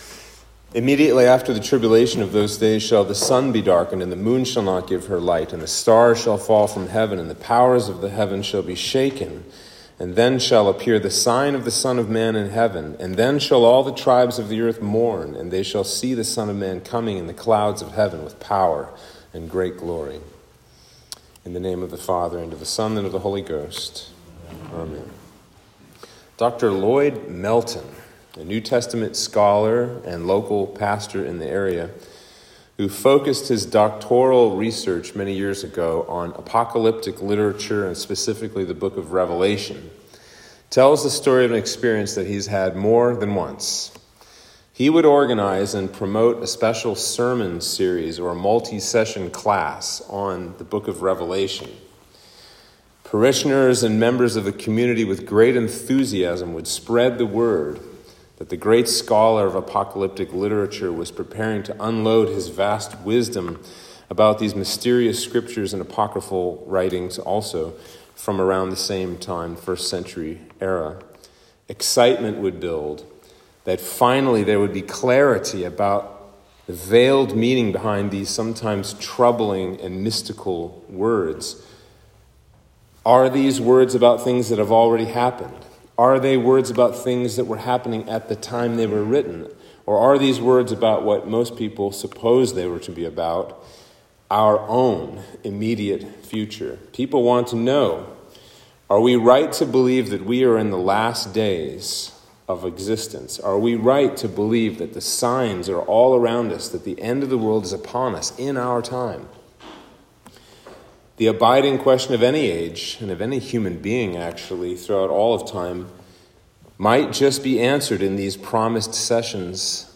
Sermon for Trinity 25